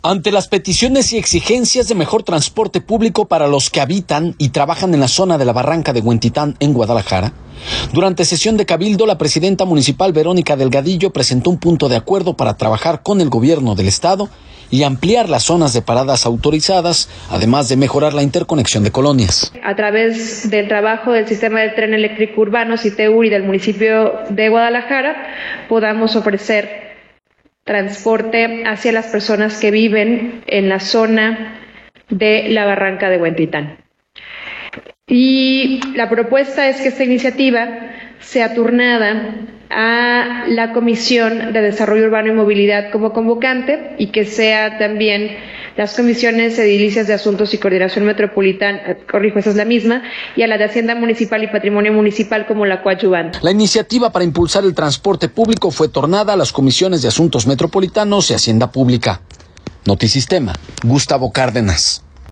audio Ante las peticiones y exigencias de mejor transporte público para quienes habitan y trabajan en la zona de la Barranca de Huentitán en Guadalajara, durante sesión de cabildo la presidenta municipal, Verónica Delgadillo, presentó un punto de acuerdo para trabajar con el gobierno del Estado y así ampliar las zonas de paradas autorizadas, además de mejorar la interconexión de colonias.